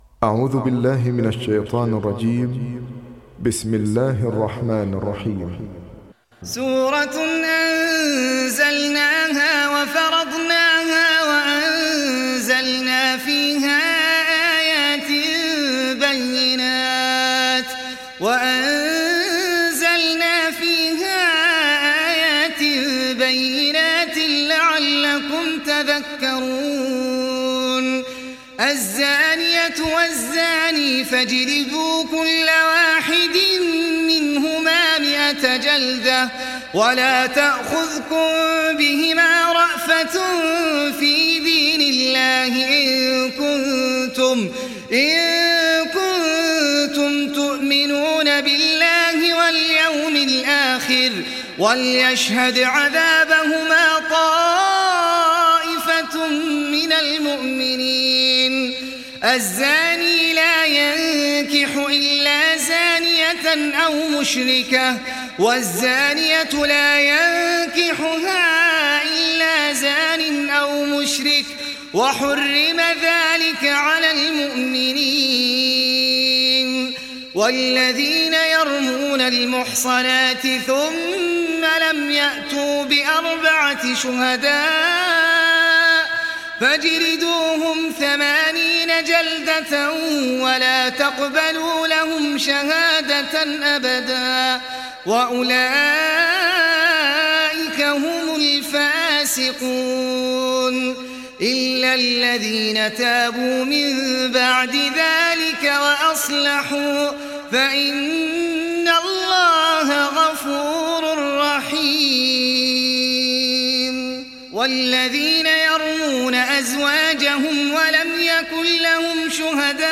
Surat An Nur mp3 Download Ahmed Al Ajmi (Riwayat Hafs)